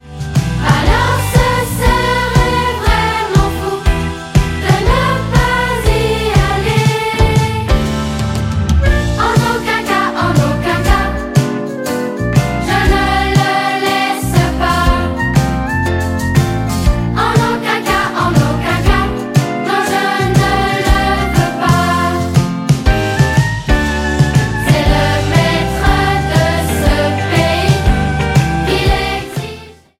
Album musical